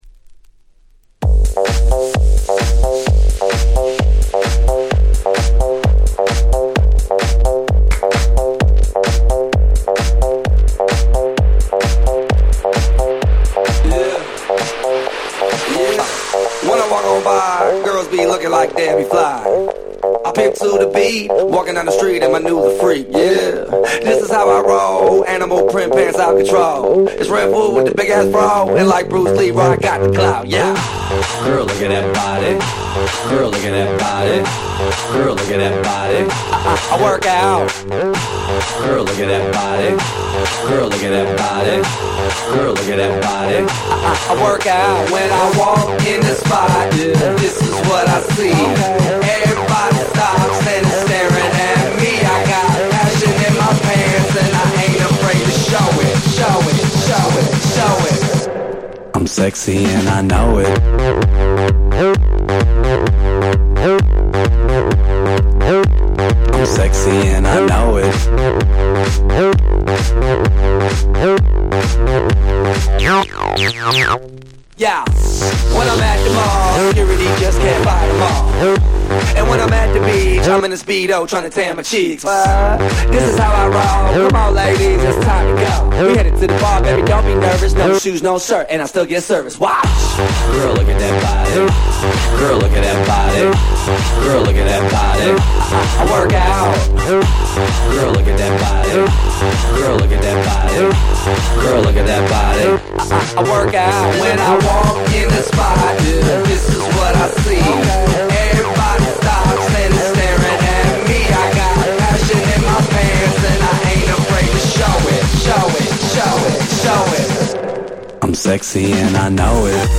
11' Super Hit EDM !!